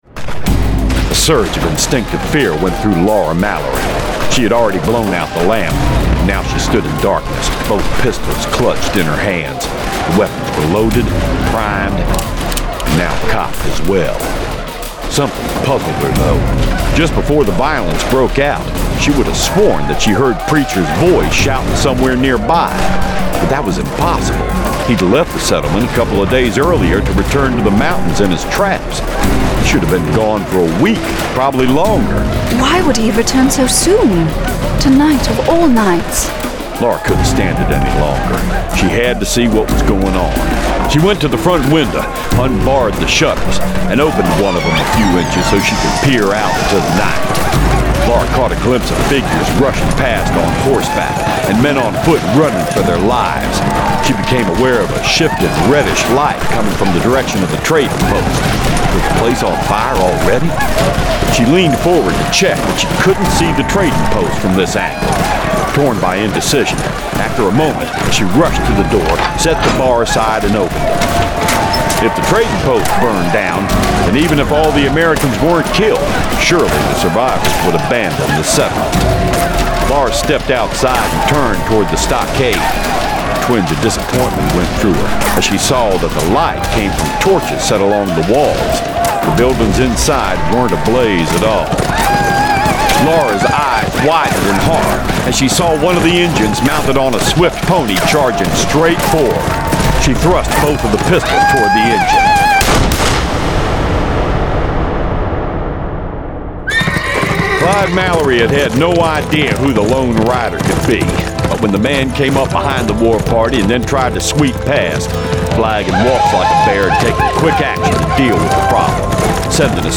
Full Cast. Cinematic Music. Sound Effects.
[Dramatized Adaptation]
Genre: Western